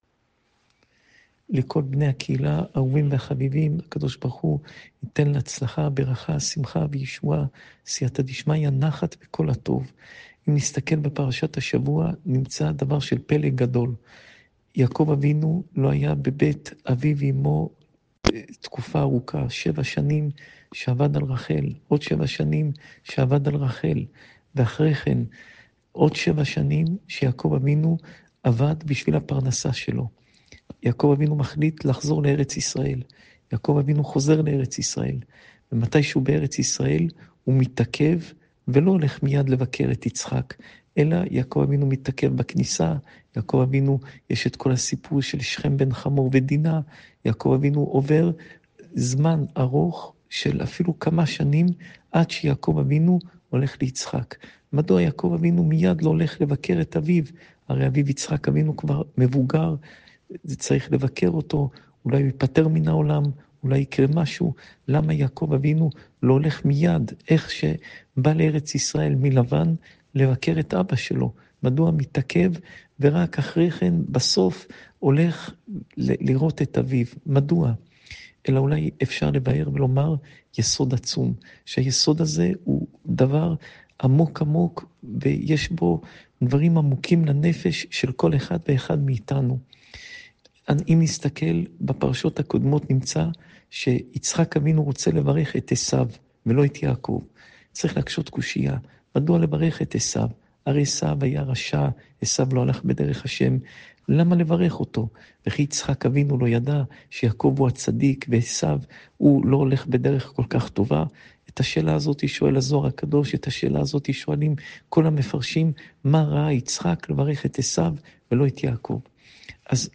שעור תורה מפי הרב פינטו